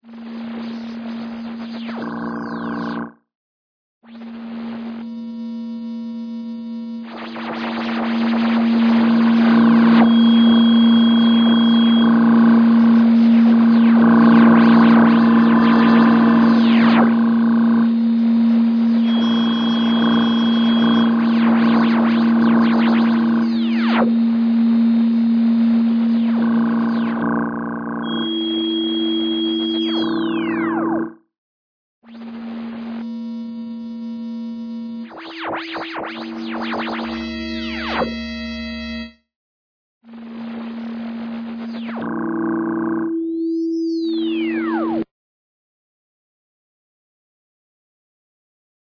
Science Fiction Sound FX - Synthesizer
Science fiction sound fx. Electronic synthesizer and computer noise.
32kbps-triond-robot.mp3